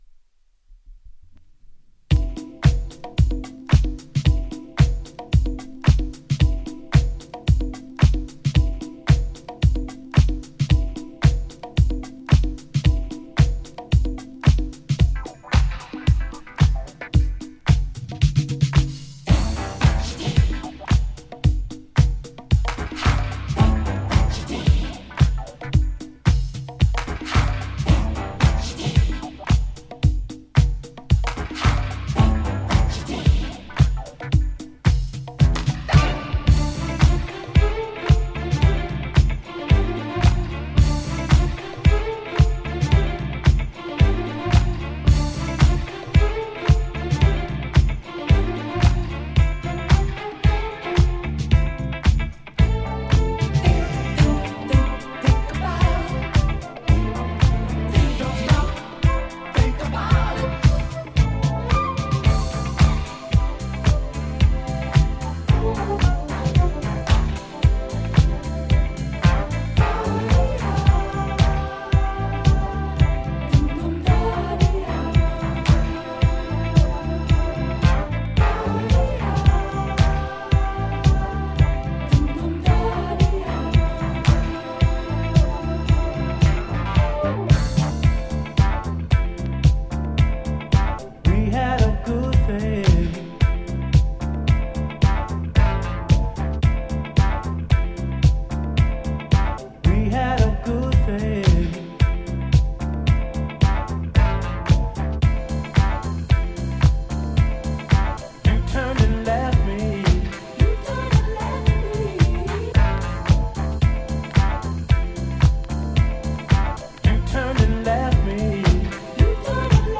ジャンル(スタイル) DISCO / NU DISCO / BALEARIC HOUSE / RE-EDIT